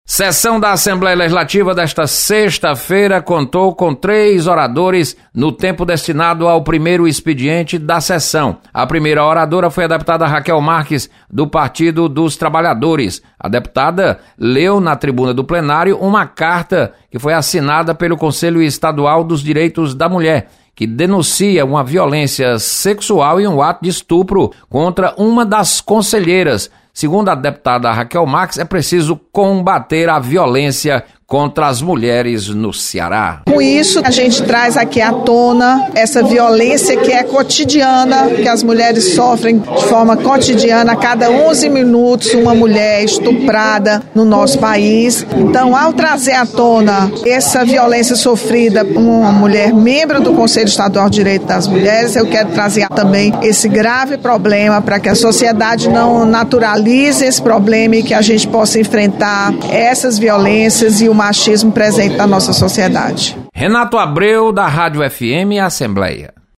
Rachel Marques repudia estupro de conselheira dos Direitos das Mulheres. Repórter